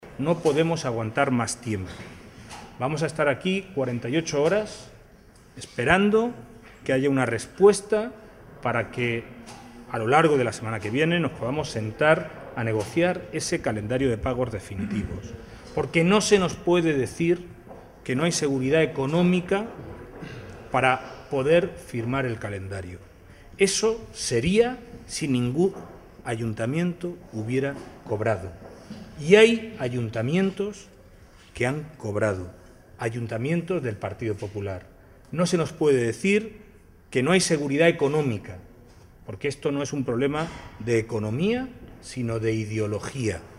Audio alcalde Puertollano-1